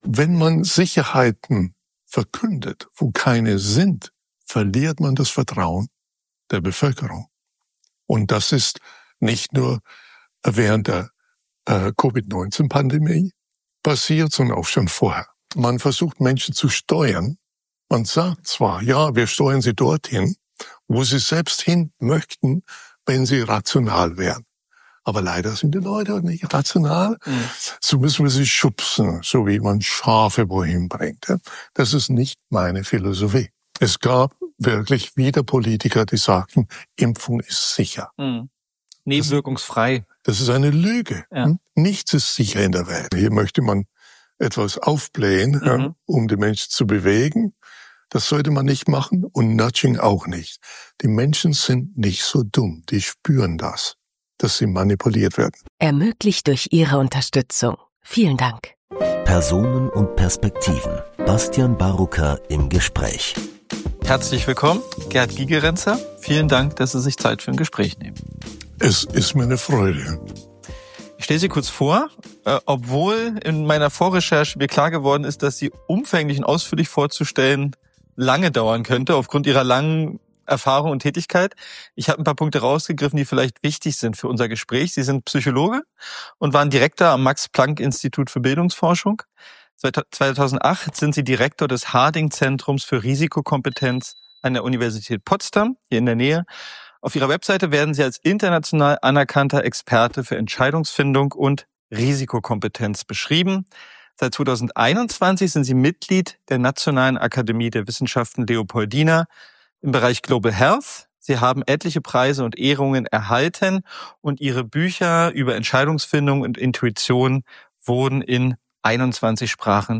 Ein Gespräch mit dem Psychologen Prof. Gerd Gigerenzer über Risikokompetenz, Entscheidungsfindung und Corona-Panikmache.